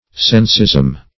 Sensism \Sens"ism\, n.